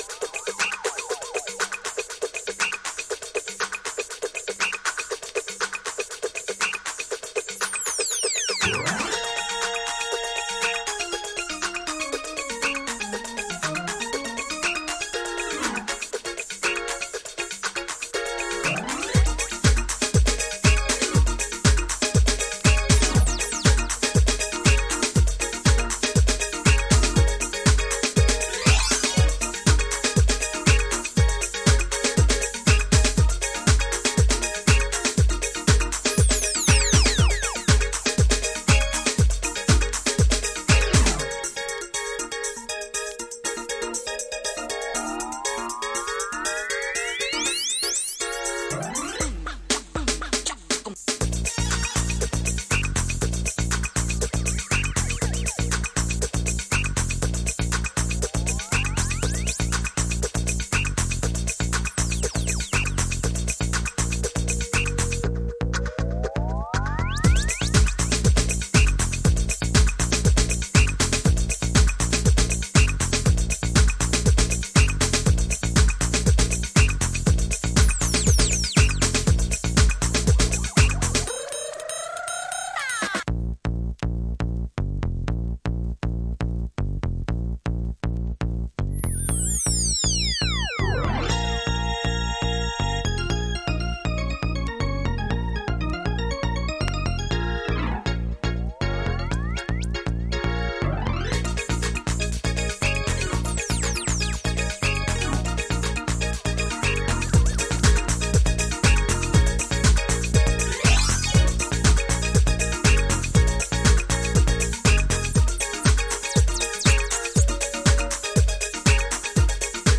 ブリープ〜レイブ対応も可能なB1推しです。